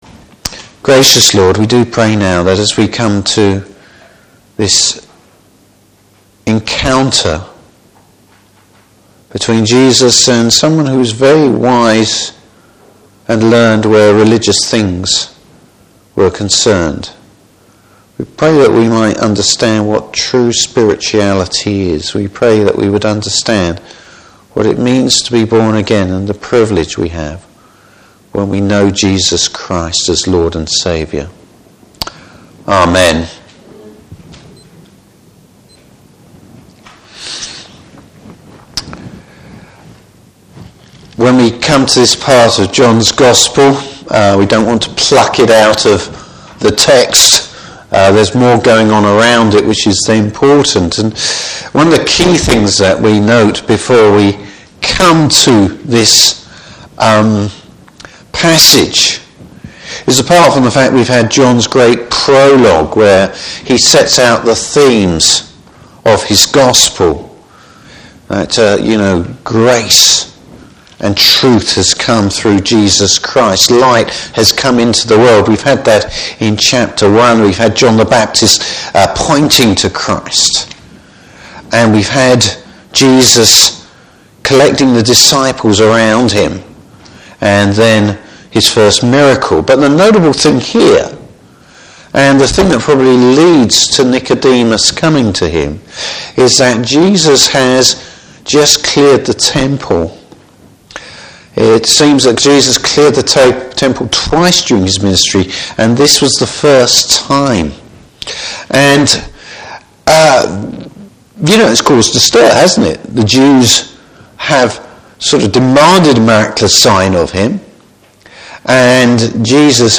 Service Type: Evening Service Bible Text: John 3:1-21.